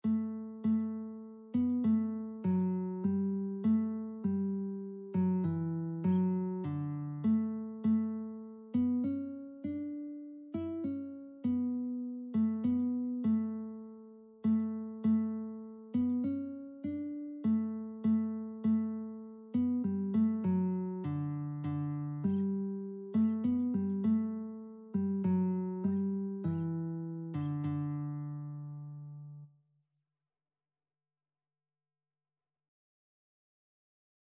Christian Christian Lead Sheets Sheet Music We Gather Together
3/4 (View more 3/4 Music)
D major (Sounding Pitch) (View more D major Music for Lead Sheets )
Traditional (View more Traditional Lead Sheets Music)